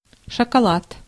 Ääntäminen
IPA : /ˈlʌk.ʃə.ɹi/